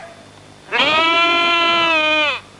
Goats Sound Effect
goats-2.mp3